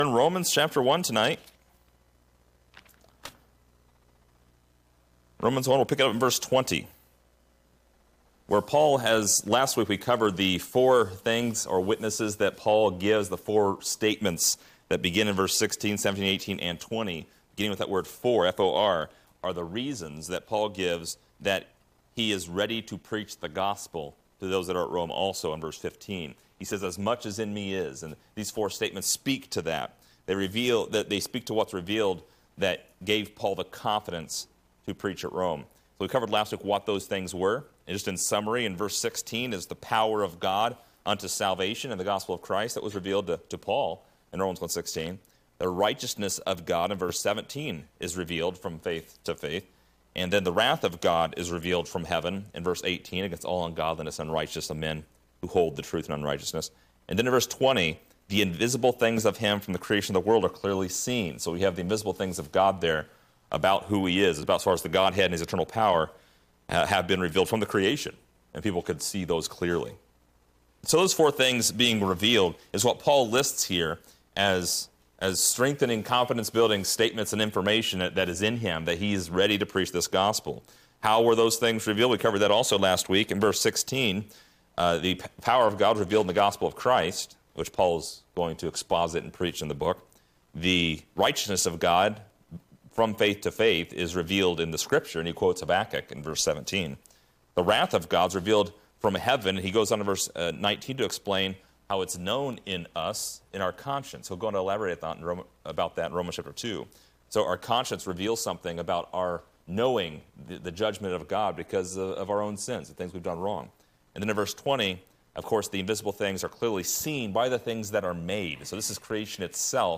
Description: This lesson is part 8 in a verse by verse study through Romans titled: They Are Without Excuse.